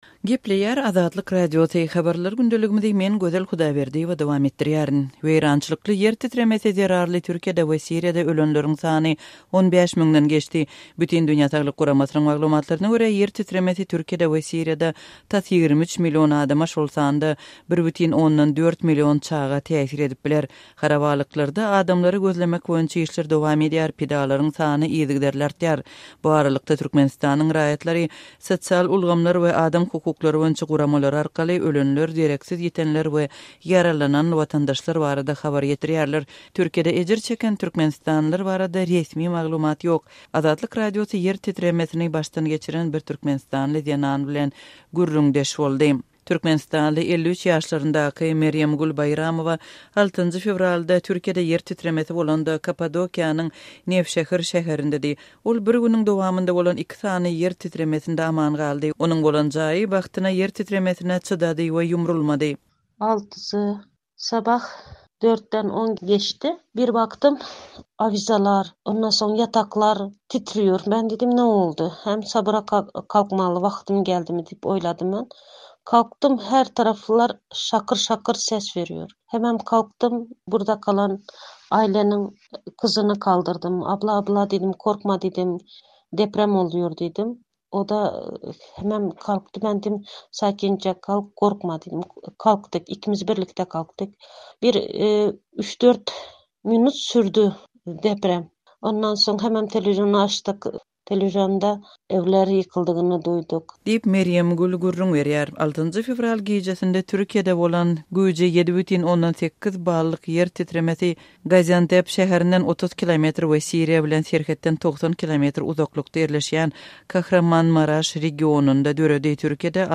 Azatlyk Radiosy ýer titresini başdan geçiren bir türkmen zenany bilen gürrüňdeş boldy.